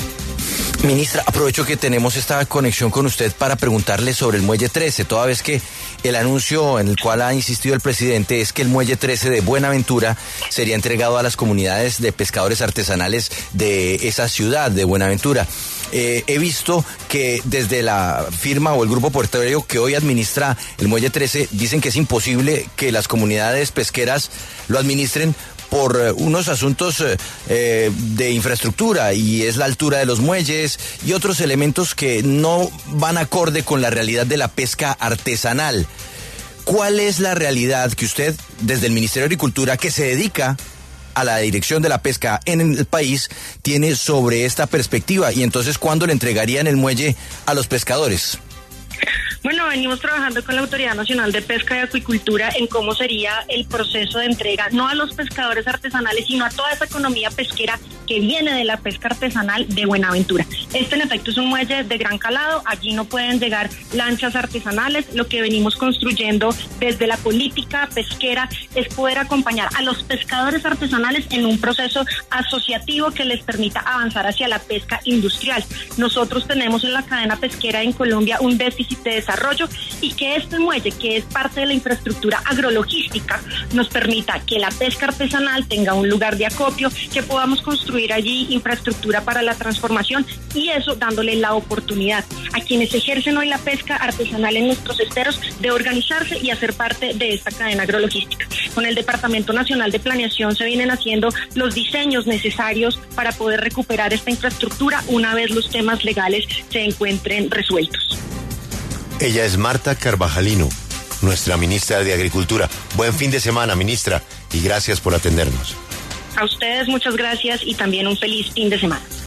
Ministra de Agricultura, Martha Carvajalino, habla en La W